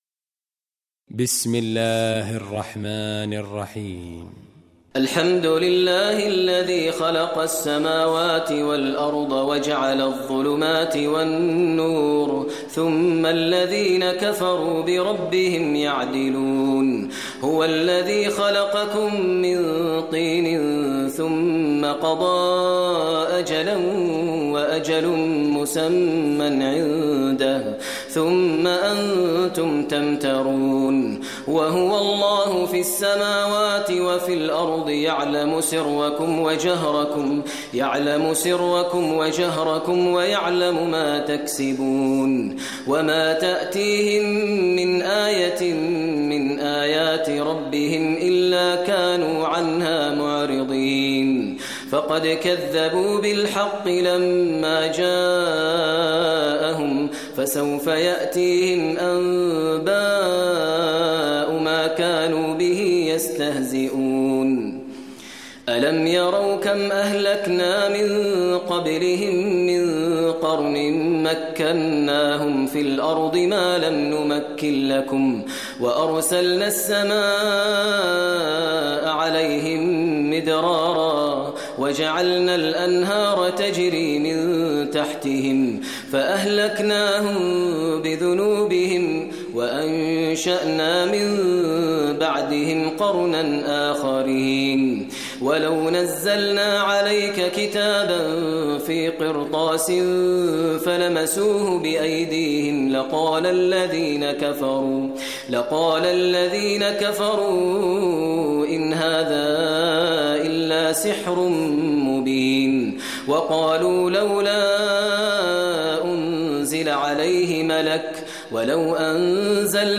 Surah Anam Recitation by Maher al Mueaqly
Surah Anam, listen online mp3 tilawat / recitation in Arabic in the voice of Sheikh Maher al Mueaqly.